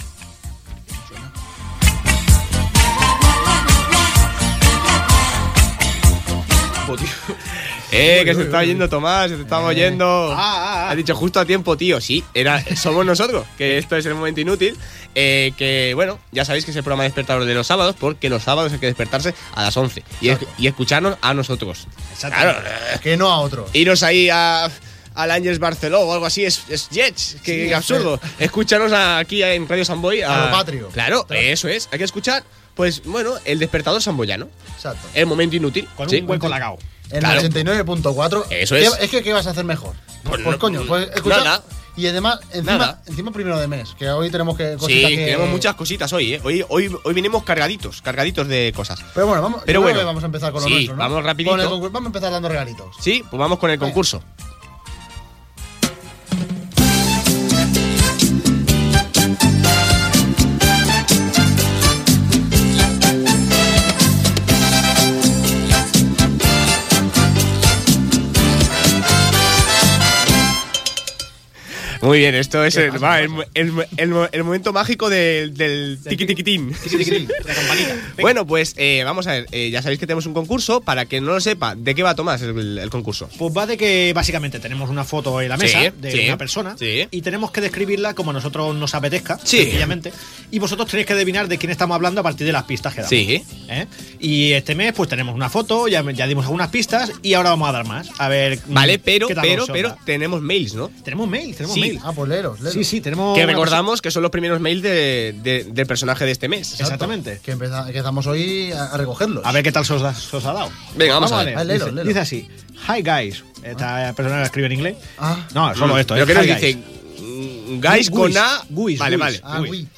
Salutació i presentació, el concurs, resum de notícies inútils Gènere radiofònic Entreteniment